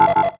beep_14.wav